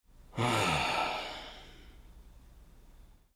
Звуки кряхтения
Недовольное кряхтение человека грубый вздох